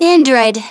synthetic-wakewords
ovos-tts-plugin-deepponies_Rise Kujikawa_en.wav